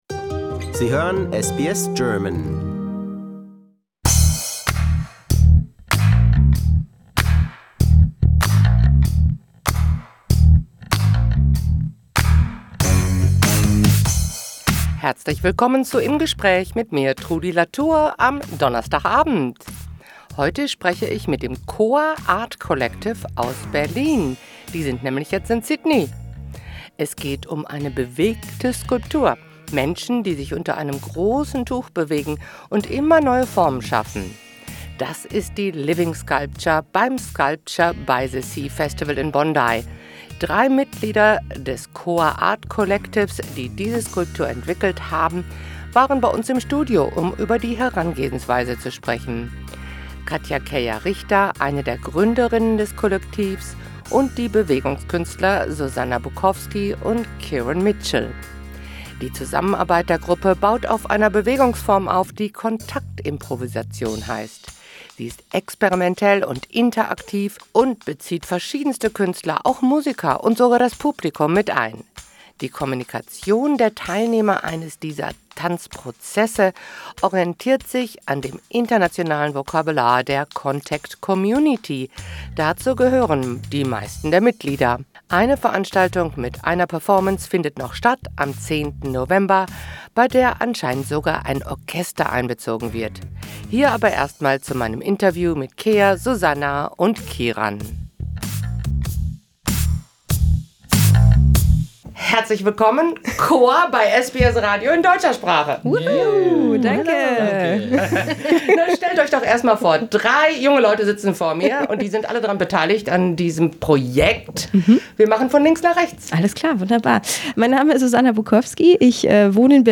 In conversation: The KOA Art Collective from Berlin in Sydney